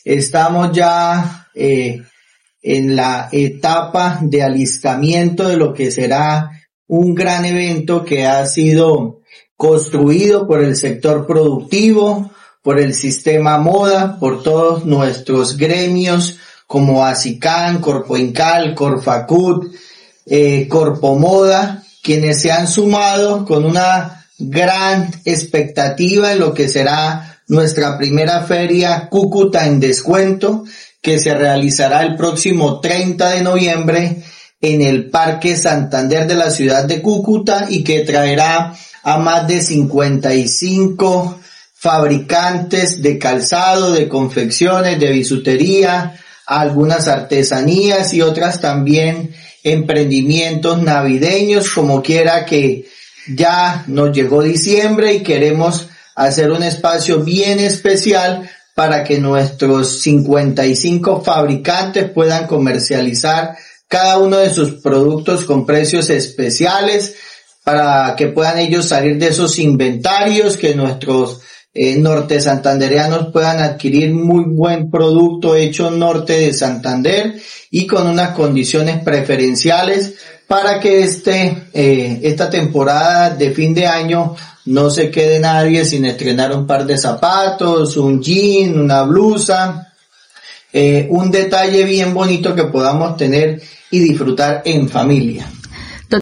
1. Audio de Olger López. secretario de Desarrollo